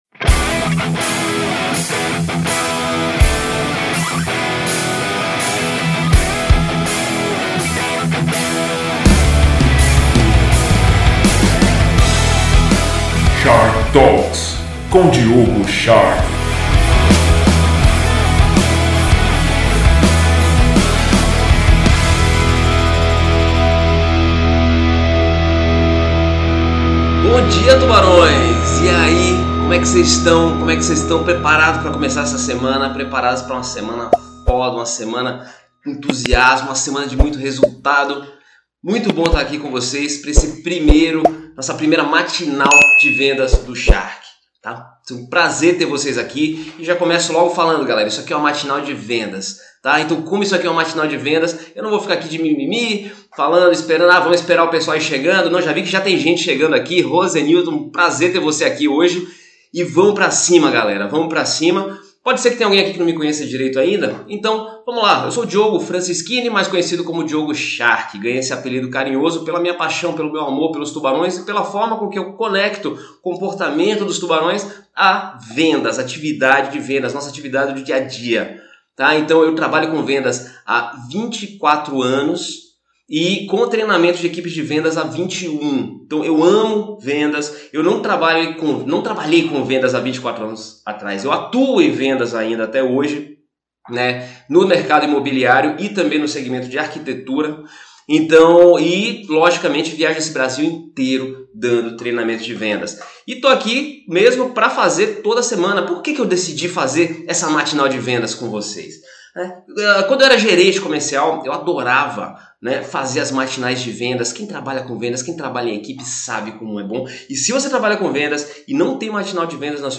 Matinal de Vendas AO VIVO, realizada todas as segundas-feiras, às 6:00 da manhã.